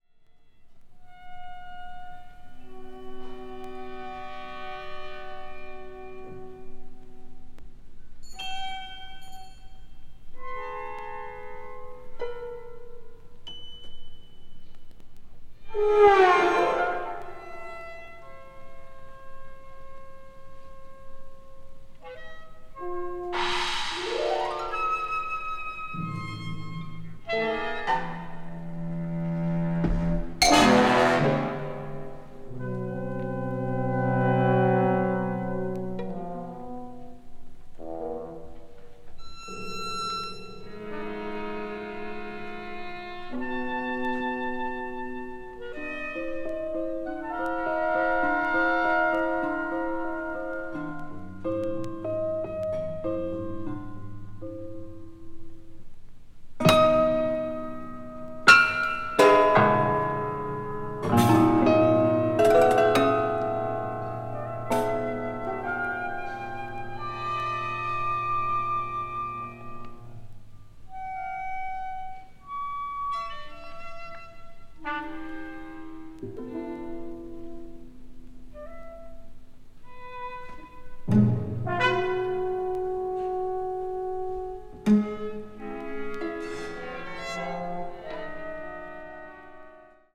media : EX/EX(some slightly noises.)
The live recording is full of realism.